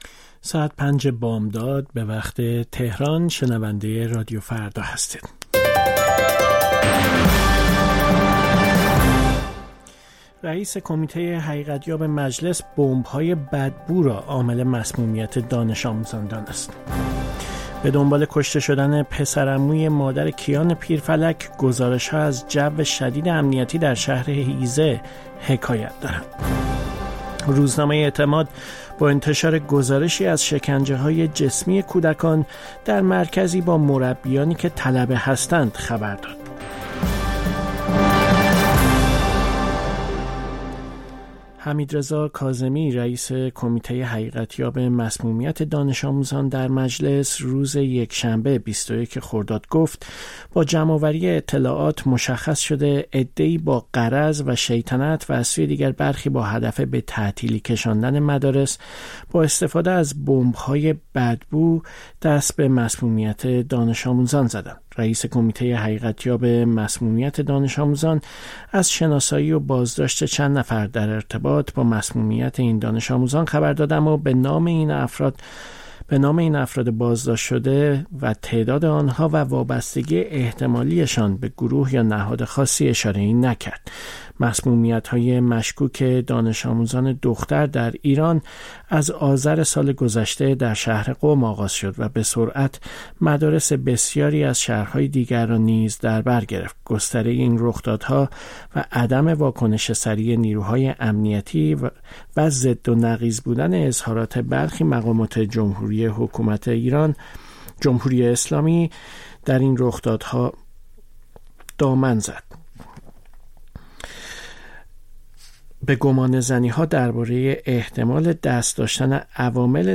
سرخط خبرها ۵:۰۰